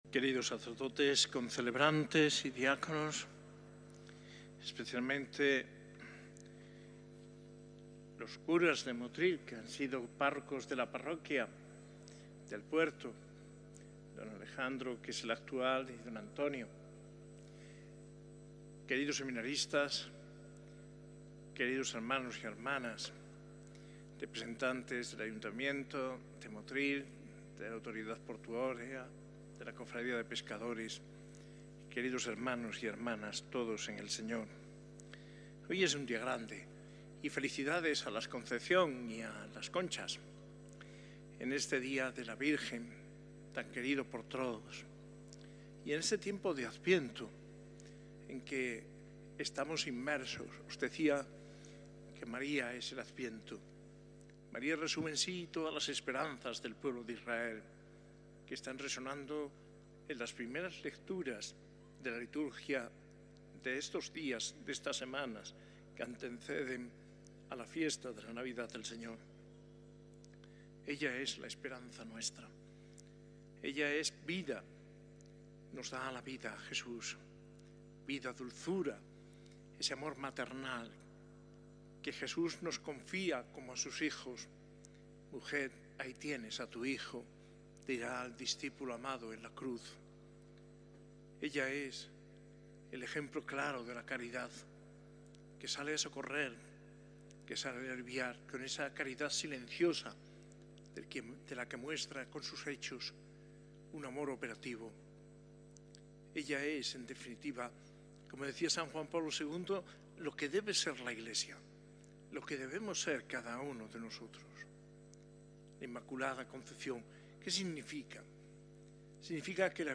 Homilía del 8 de diciembre de 2024, día de la Solemnidad de la Inmaculada Concepción y II domingo de Adviento.